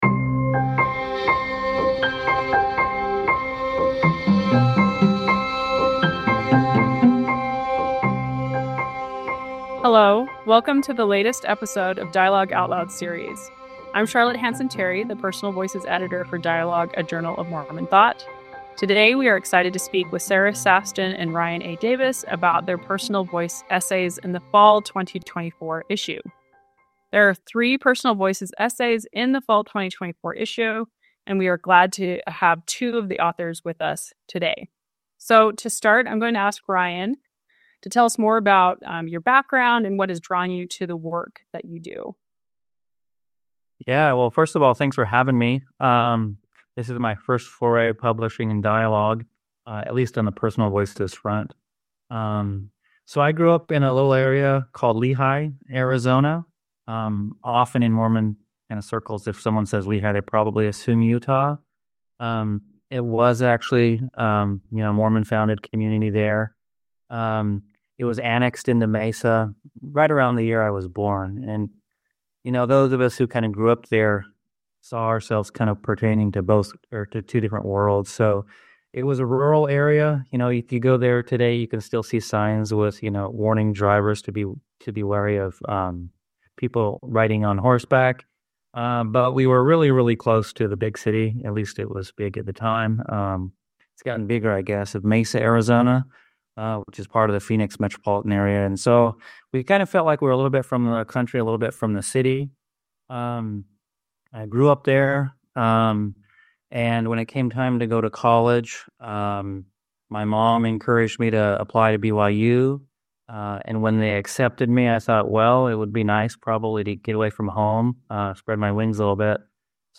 Personal Metaphors: A Conversation